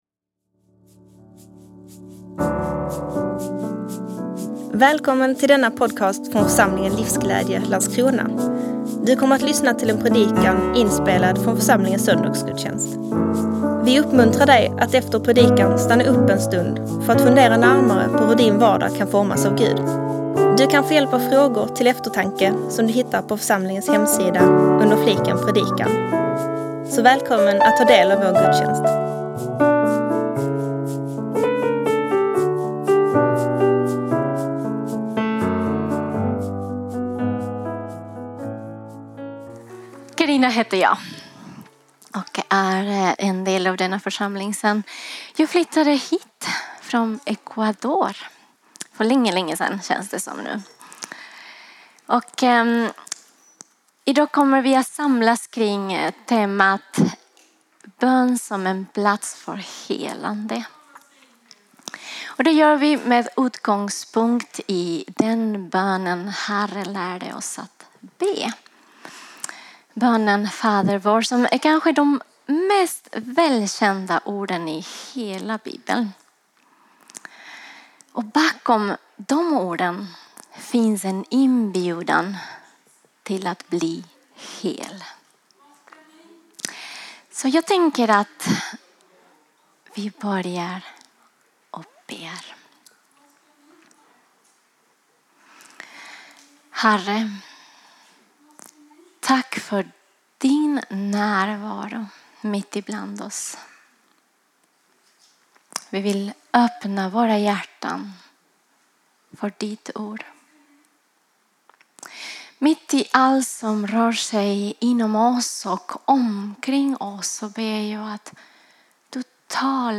läkedom och relation Predikant